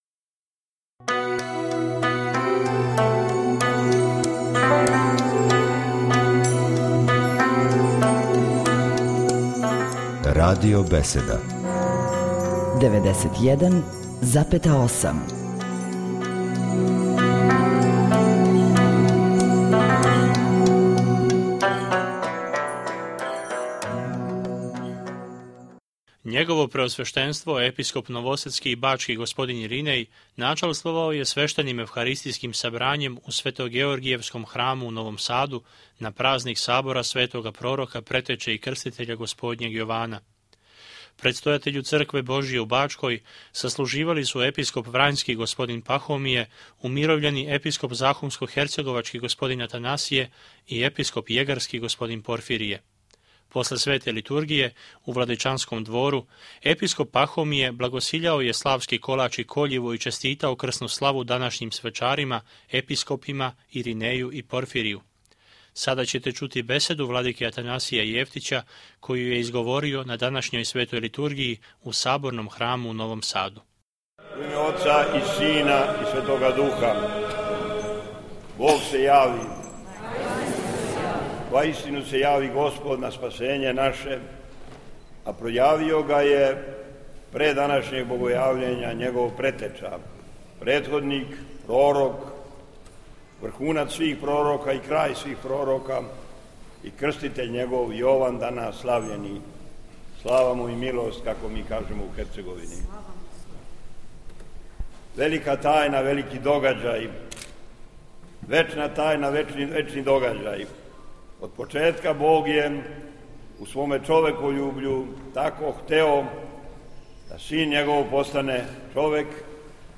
• Беседа Епископа Атанасија: